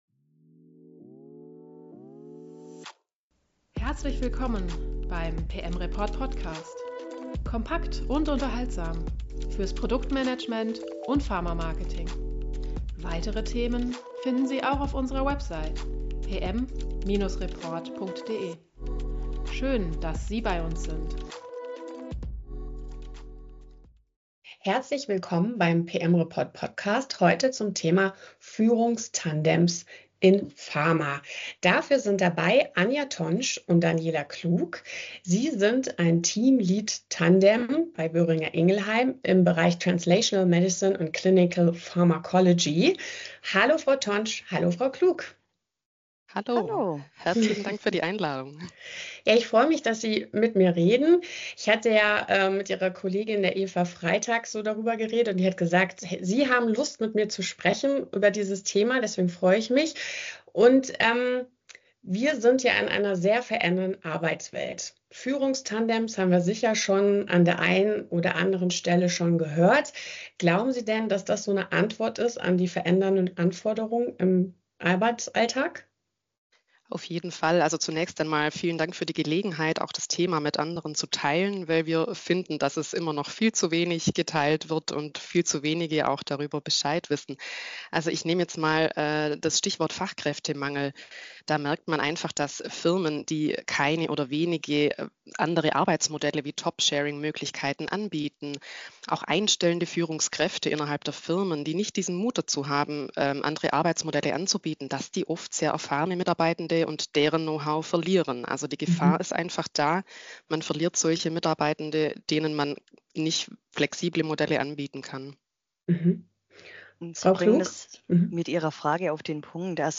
Mit einer sich wandelnden Arbeitswelt verändern sich auch die Anforderungen an Führung. Ein Ansatz könnten Führungstandems sein. Im Gespräch darüber mit einem Führungstandem bei Boehringer Ingelheim